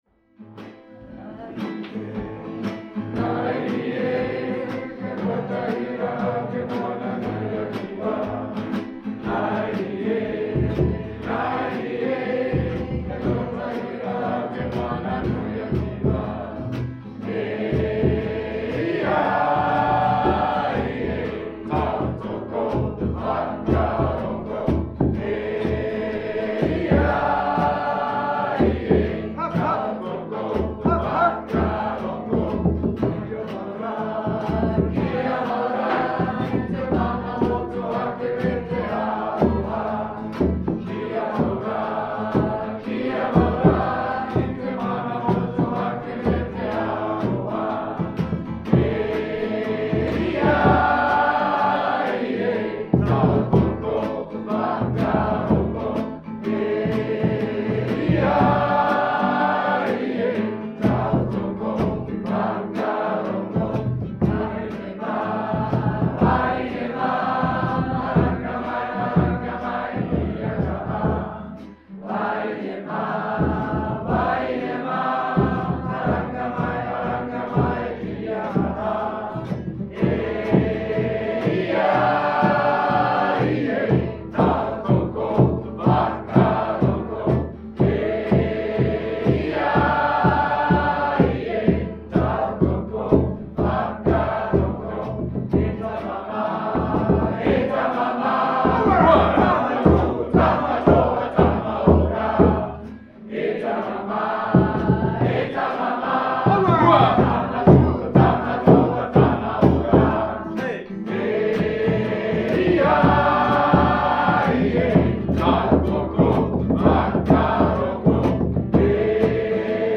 Abschluss mit dem Maorilied „Nga iwi e“
Mit dem kraftvollen Lied „Nga iwi e“ – „Unterstützt Euch und seid stark“ – kannst Du Dich in die Stimmung nach der Zeremonie einstimmen.